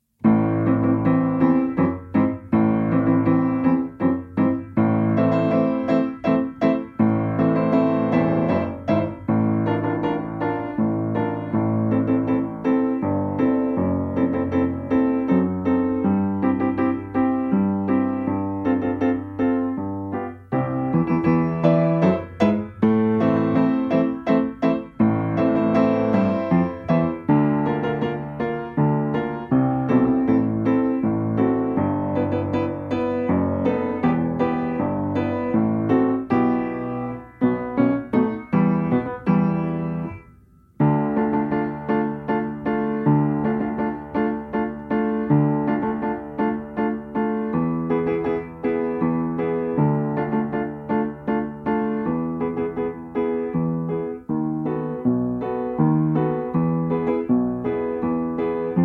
Strój 440Hz
II wersja – ćwiczeniowa
Tempo: 80 bmp
Nagrane z metronomem.
Nagranie nie uwzględnia zwolnień.
piano